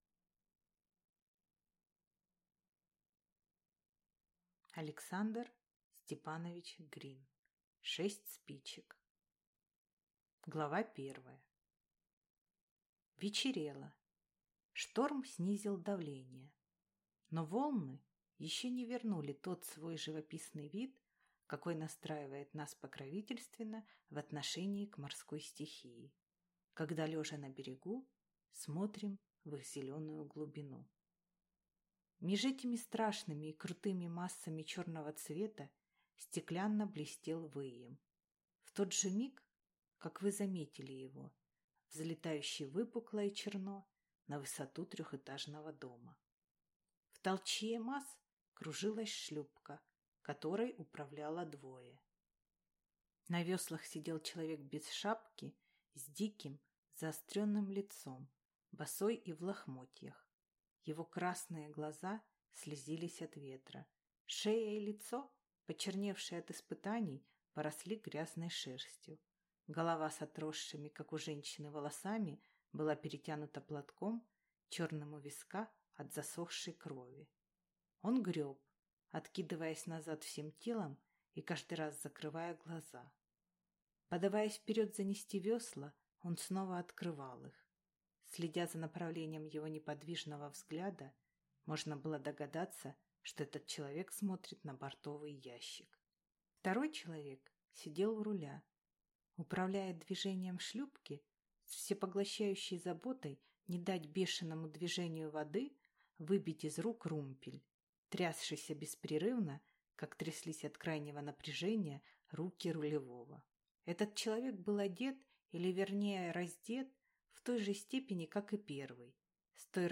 Аудиокнига Шесть спичек | Библиотека аудиокниг